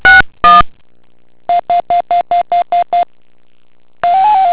These audio recordings demonstrate how the RTS-2 is accessed manually, access line identification is performed, test calls are placed, and the RTS-2 is released.
2. Access Line Identification. In this clip, the user requests the RTS-2 line number which answered the incoming access call.
Distinctive tone sequences acknowledge command acceptance or rejection due to an error.